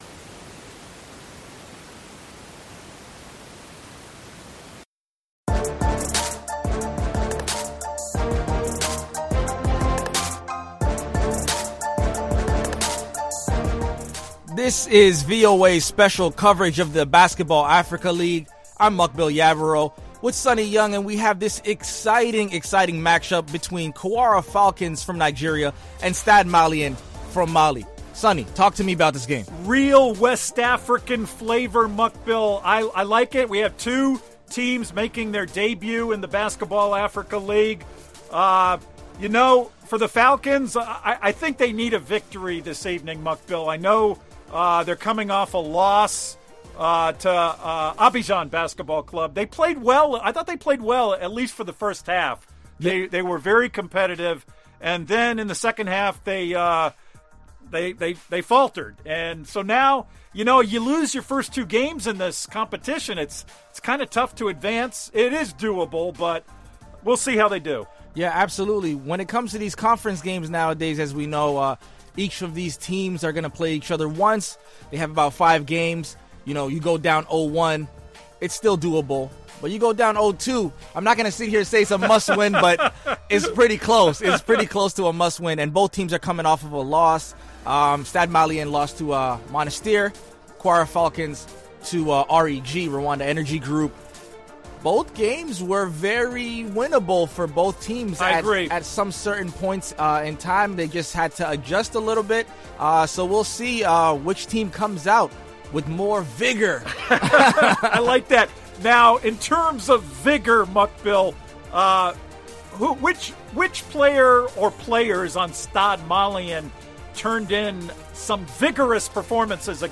Basketball Africa League season three game play: Kwara Falcons vs Stade Malien.